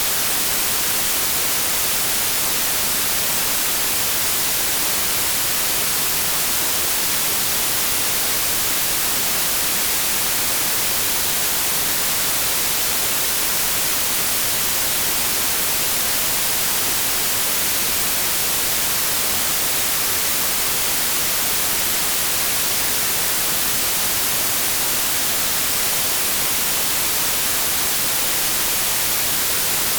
De manière similaire, un bruit blanc contient toutes les fréquences audio à des niveaux d'intensité égaux.
Bruit Blanc
bruitblanc.mp3